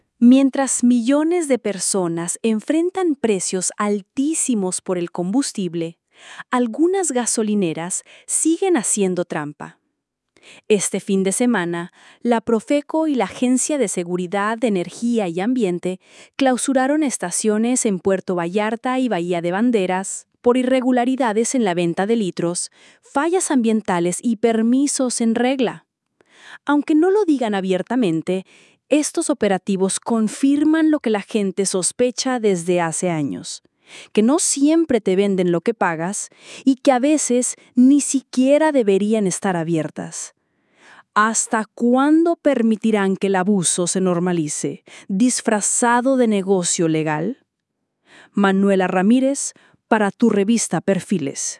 COMENTARIO EDITORIAL